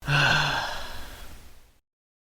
Man Sigh 3
yt_PiibxV41wM0_man_sigh_3.mp3